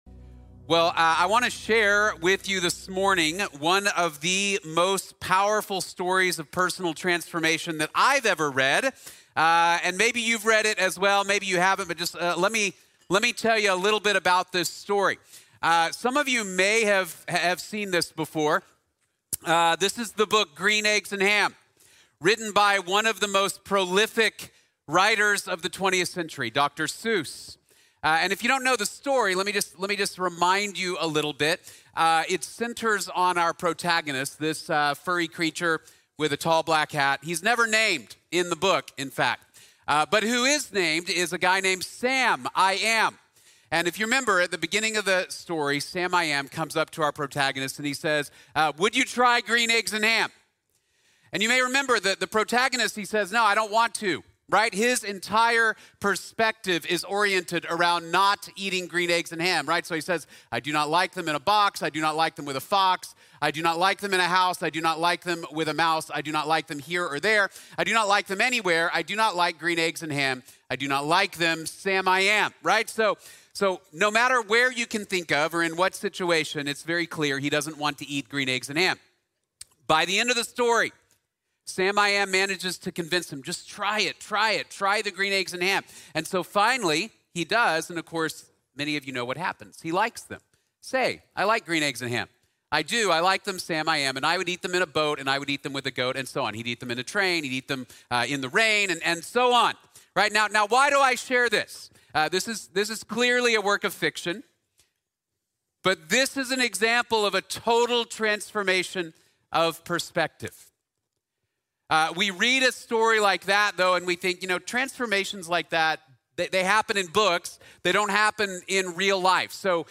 A Total Transformation | Sermon | Grace Bible Church